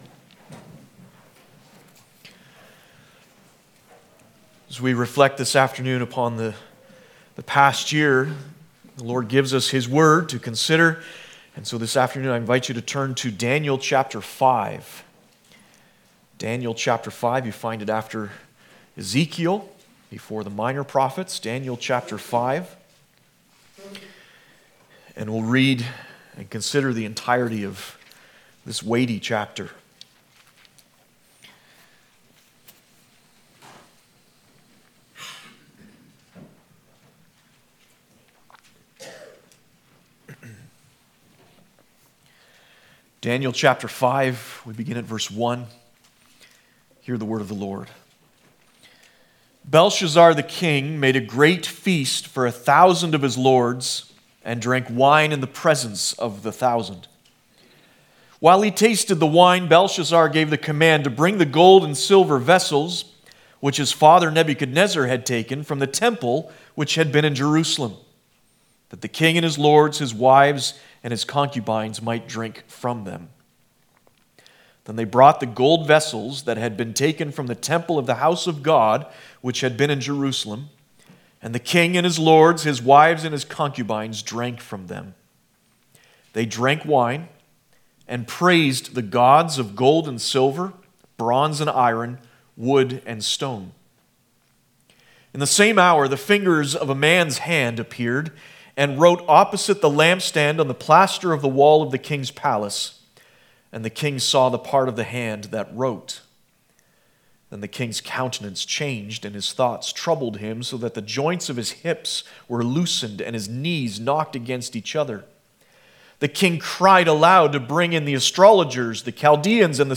Daniel 5 Service Type: New Year's Eve « Jesus said